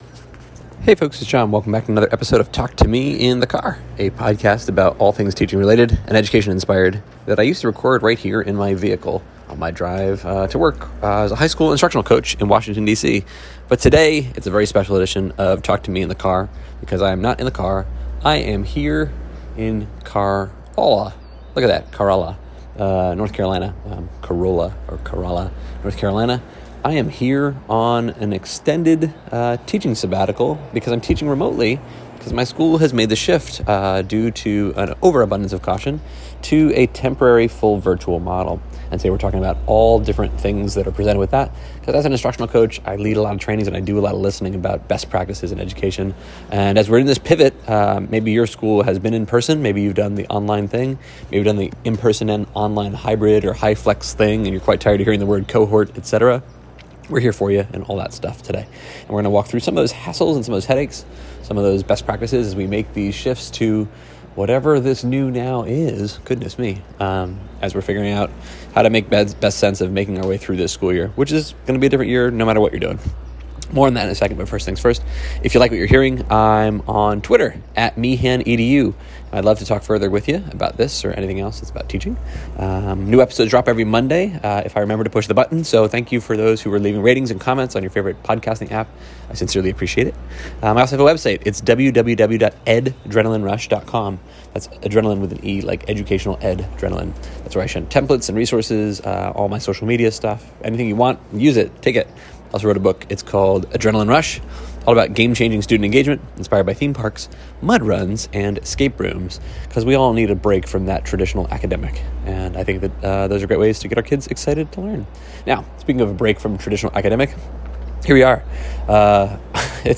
Greetings from an unexpected spell of virtual instruction in sunny Corolla, North Carolina!